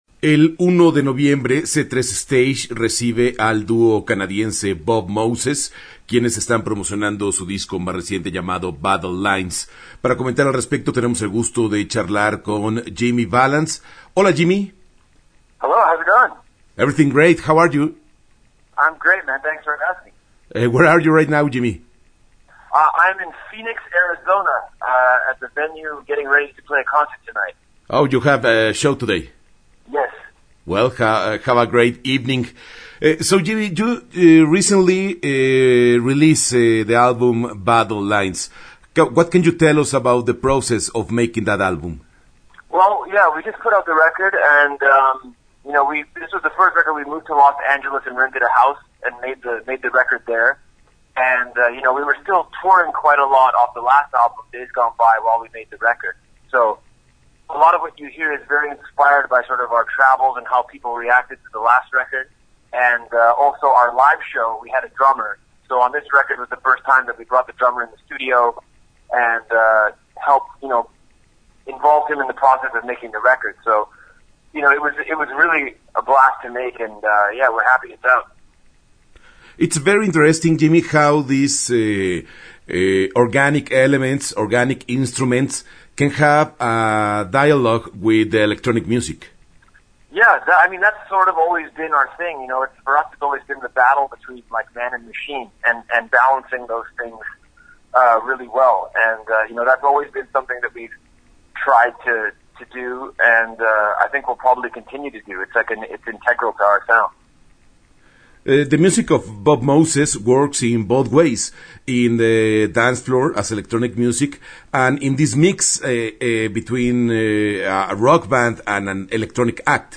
Sobre la creación de Battle Lines, su gira y su presentación en Guadalajara, conversamos con Jimmy Vallance, integrante de Bob Moses.
Entrevista-Bob-Moses-2018-web.mp3